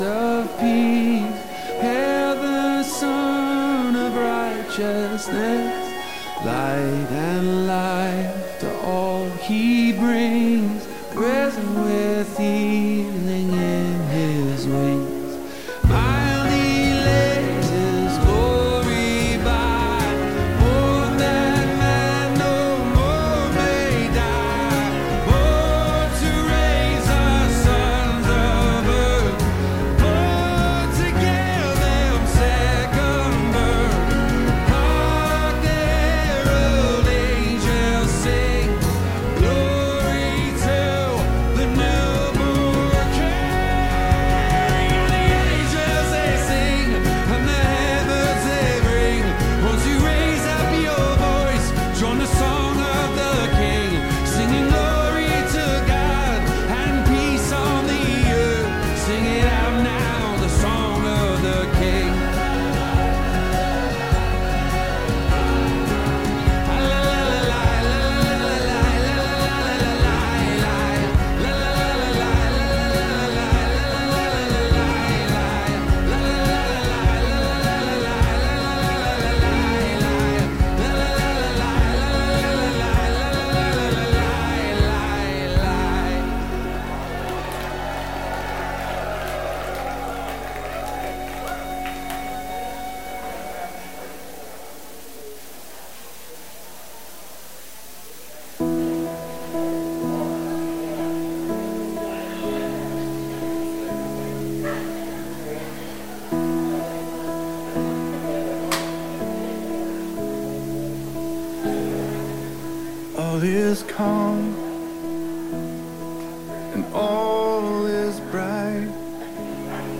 Christmas Sermon Series, Emmanuel, God With Us, Part 2